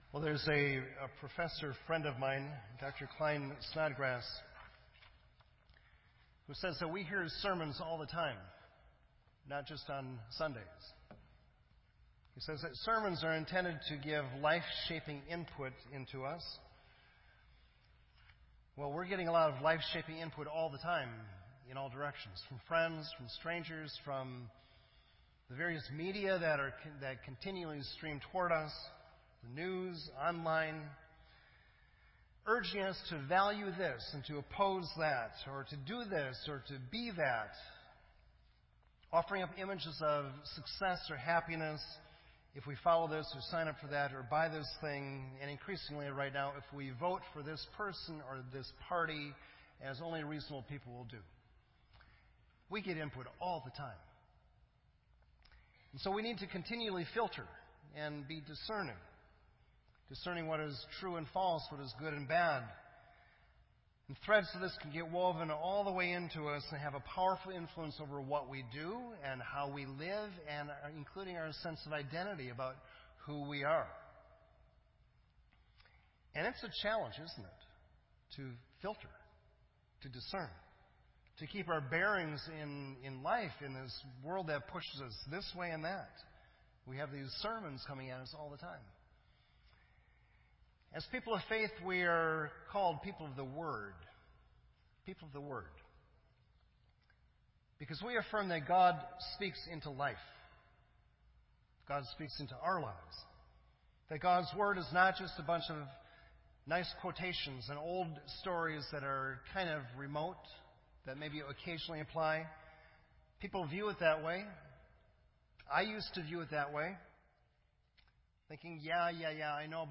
This entry was posted in Sermon Audio on September 17